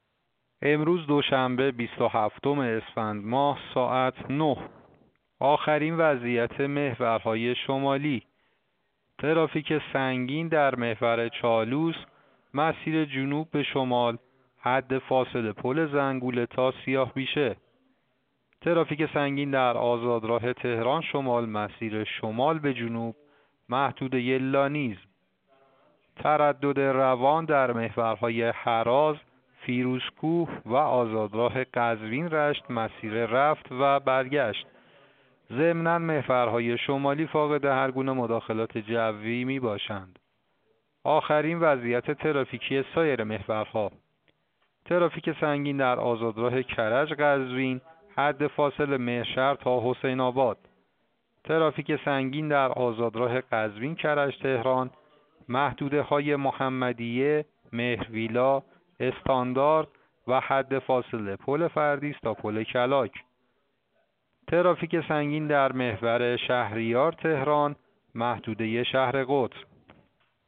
گزارش رادیو اینترنتی از آخرین وضعیت ترافیکی جاده‌ها ساعت ۹:۱۵ بیست و هفتم اسفند؛